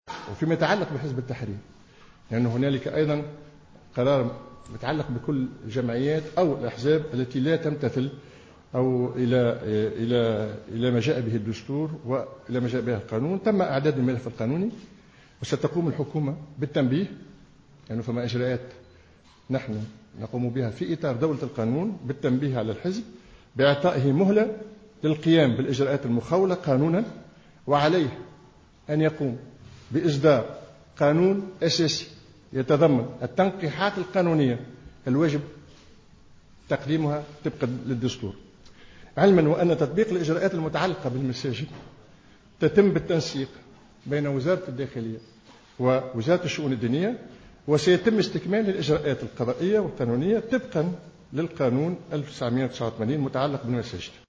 أكد كمال الجندوبي الوزير لدى رئيس الحكومة المكلف بالعلاقة مع الهيئات الدستورية والمجتمع المدني خلال ندوة صحفية عقدت اليوم الخميس 02 جويلية 2015 بالقصبة أنه تم تنبيه رئيس حزب التحرير رضا بلحاج وإعطاؤه مهلة للقيام بالاجراءات المخولة قانونا وعليه إصدار قانون أساسي يتضمن التنقيحات القانونية الواجب تقديمها طبقا للدستور.